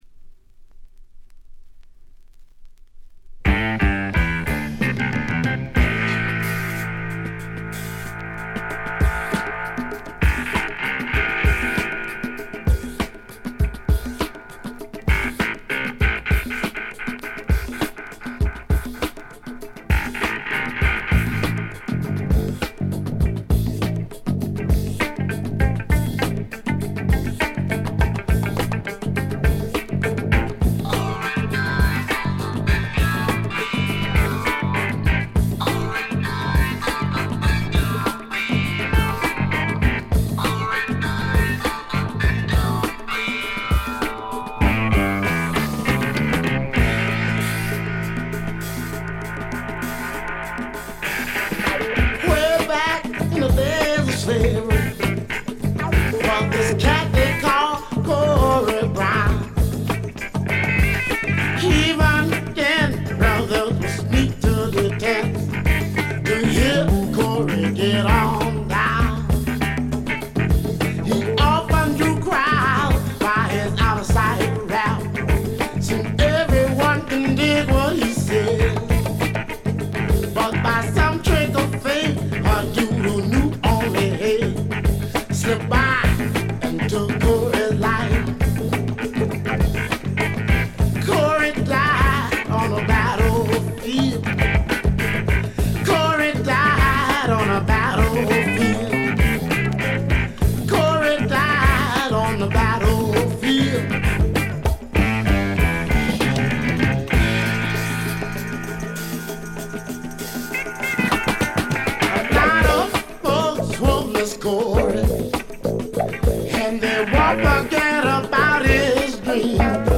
わずかなノイズ感のみ。
マルディ・グラ・インディアンの伝統と強力なファンク・サウンドの融合ともいうべきエポック・メイキングな名作ですね。
試聴曲は現品からの取り込み音源です。